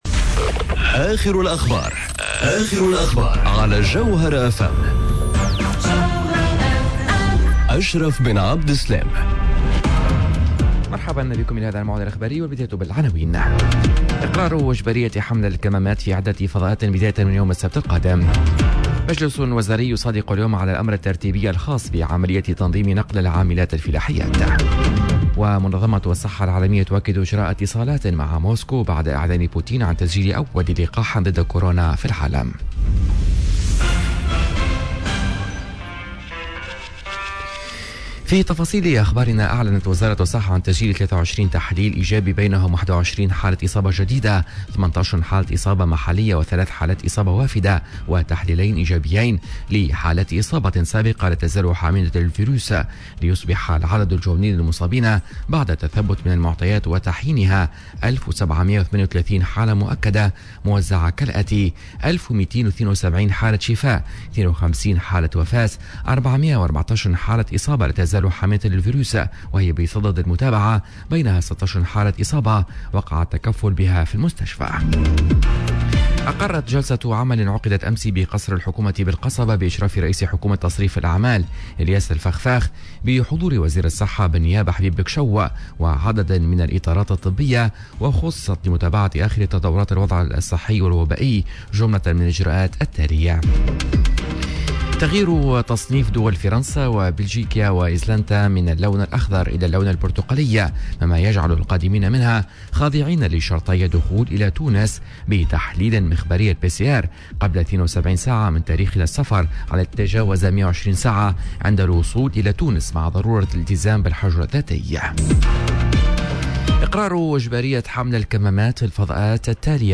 نشرة أخبار السابعة صباحا ليوم الإربعاء 12 أوت 2020